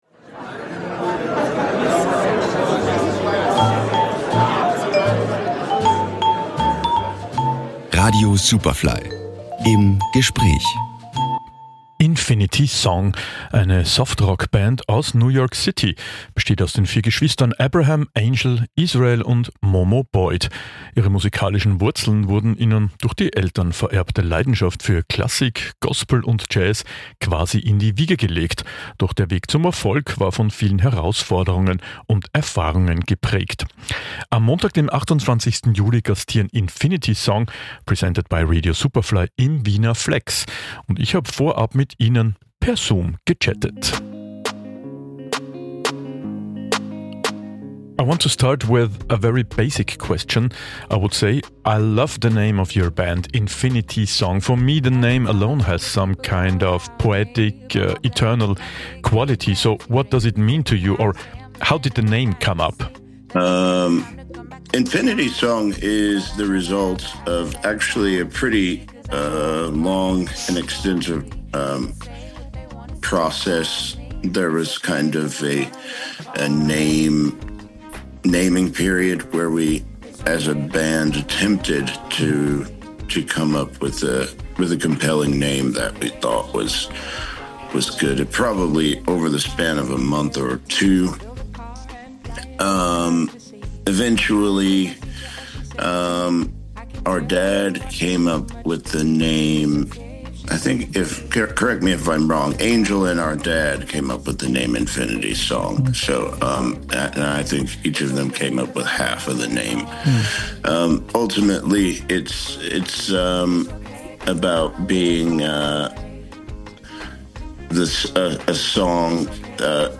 Superfly Interviews | Infinity Song